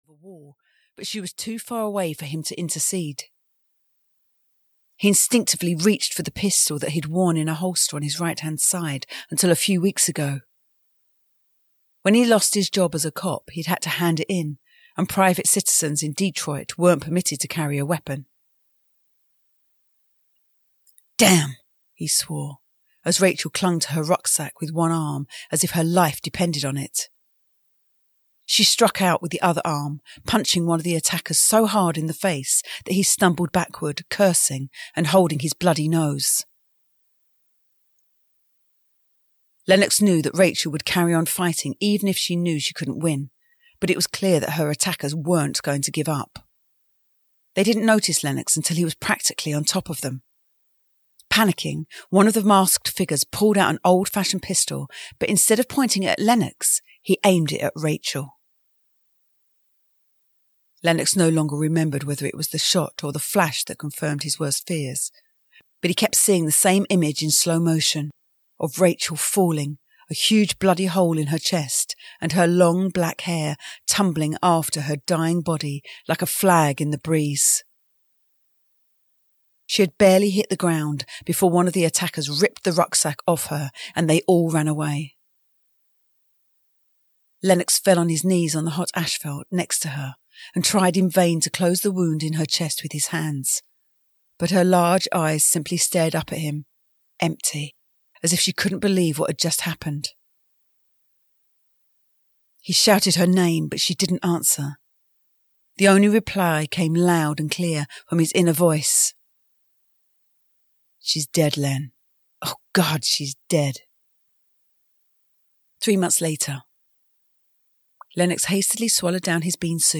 Audio knihaRobolove 2 - Operation: Copper Blood (EN)
Ukázka z knihy